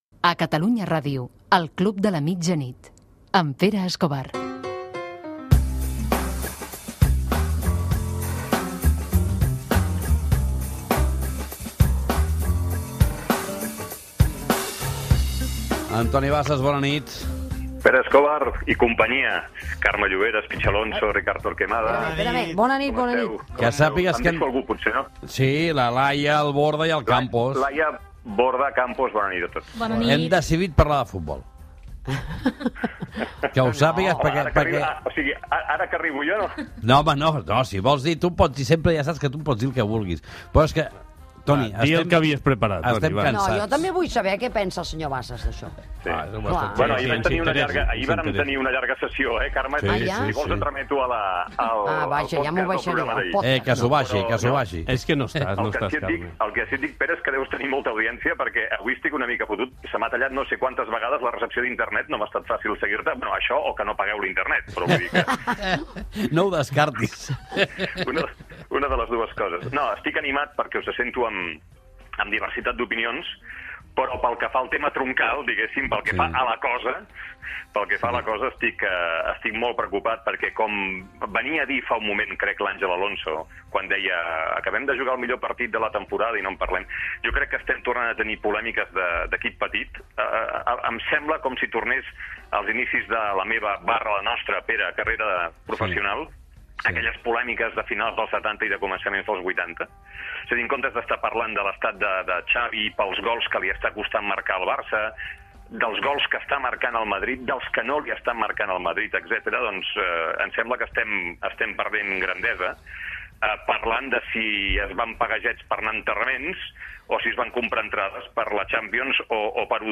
Indicatiu del programa
Gènere radiofònic Esportiu